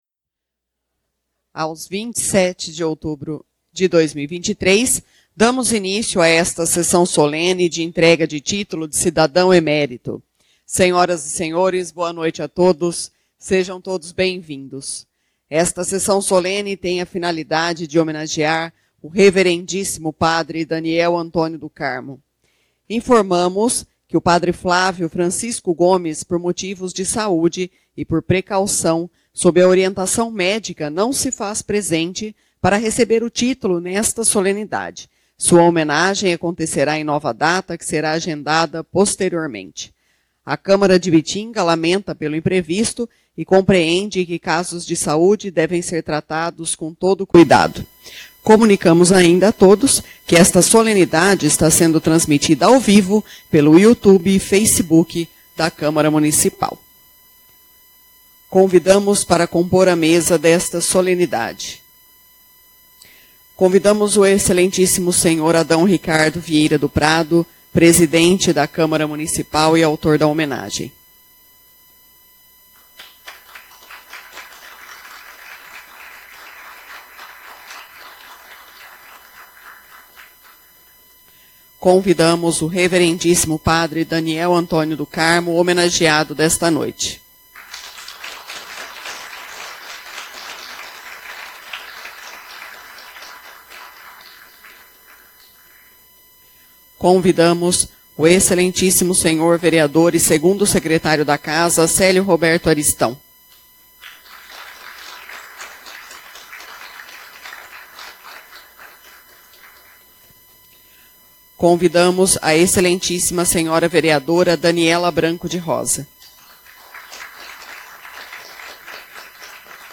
Sessões Solenes/Especiais